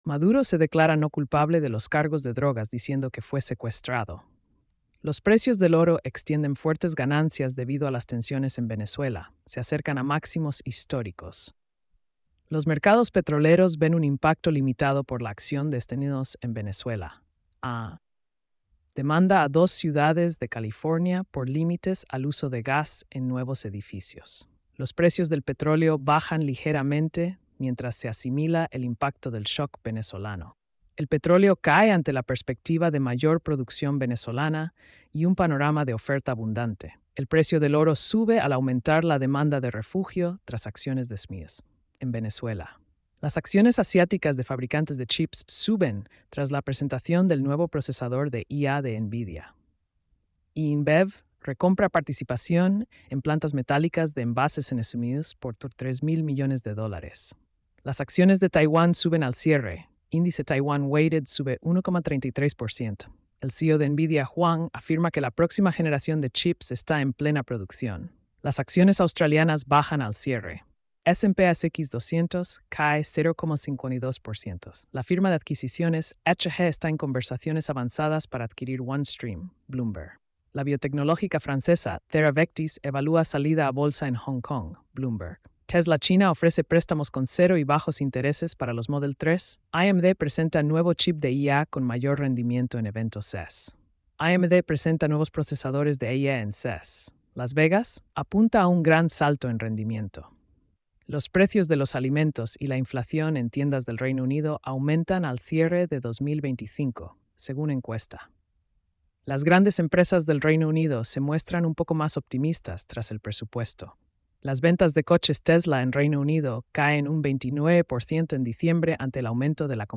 🎧 Resumen Económico y Financiero.